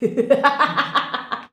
LAUGH 2.wav